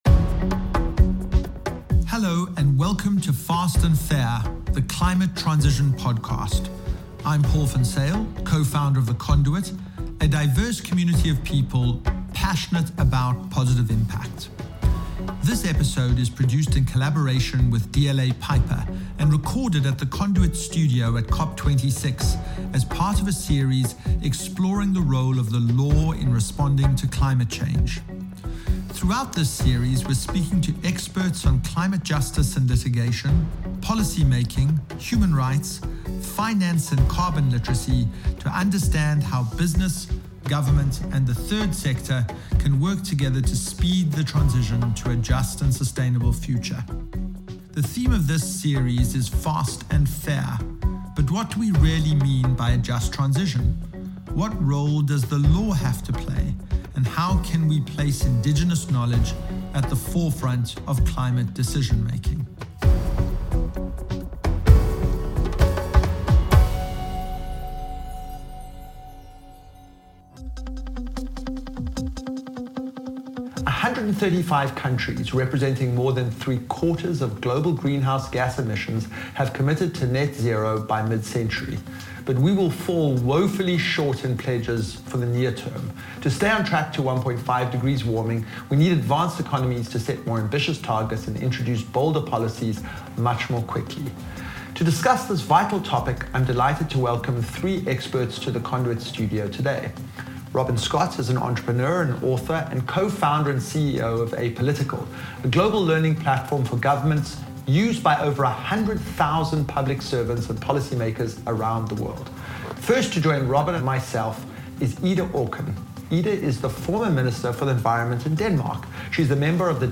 They discuss why governments need to adapt their strategies for urgent climate mitigation, the unique challenges that policy makers face across the globe, and how we can ensure that bold new policies are implemented effectively. This episode is produced in collaboration with DLA Piper, and recorded at The Conduit Studio at COP26, as part of a series exploring the role of the law in responding to climate change.